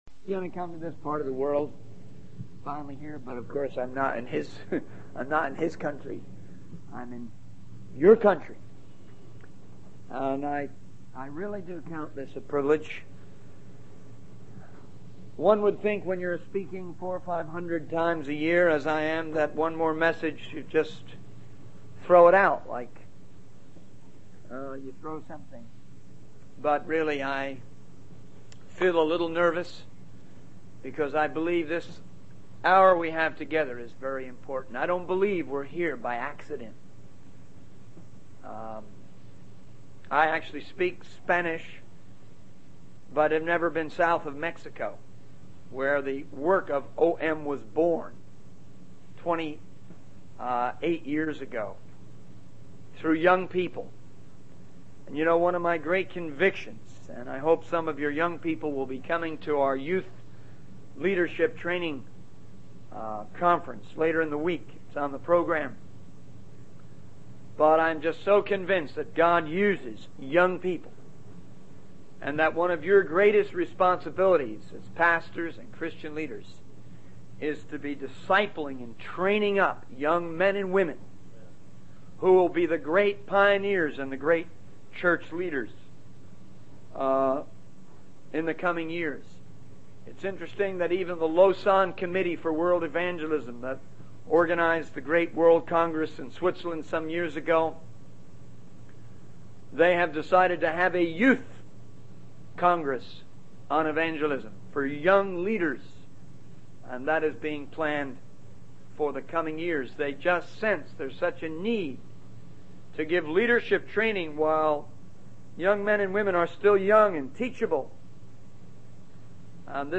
In this sermon, the speaker shares his excitement about finally being in a different country to preach the word of God. He talks about his involvement with OM, a missionary organization, and their work in different parts of the world.